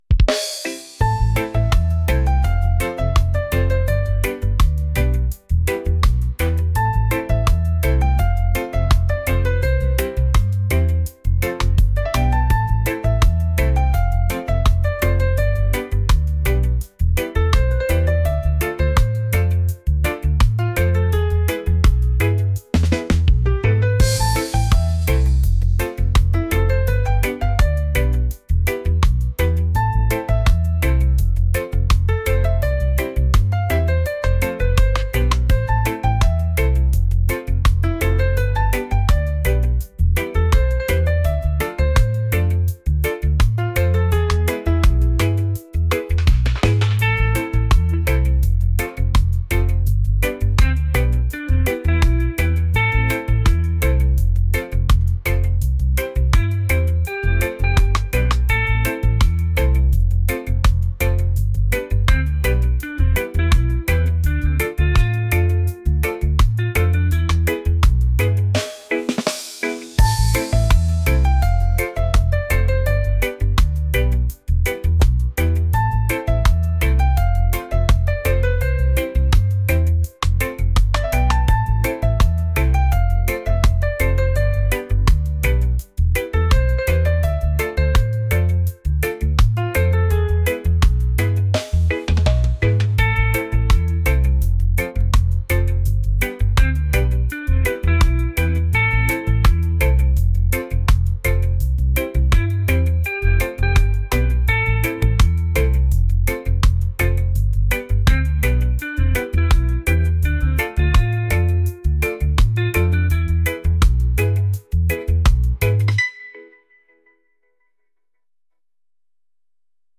reggae | romantic | relaxed